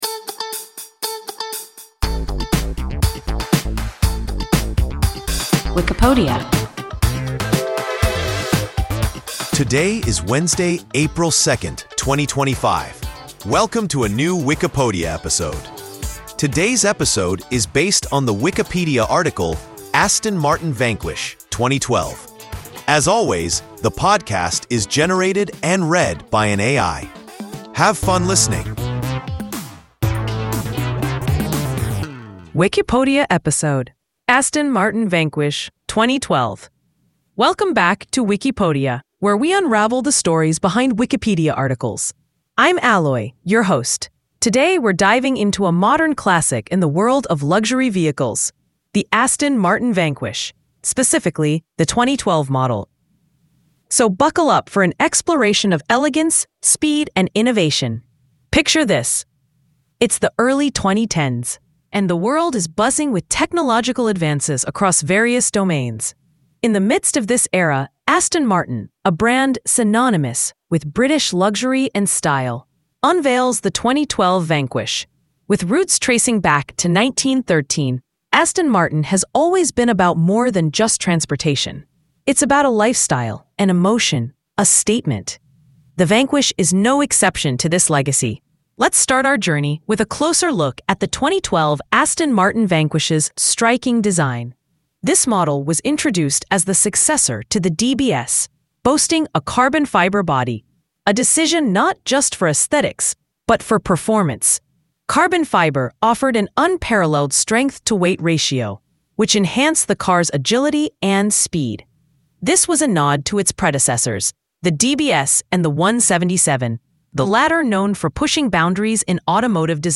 Aston Martin Vanquish (2012) – WIKIPODIA – ein KI Podcast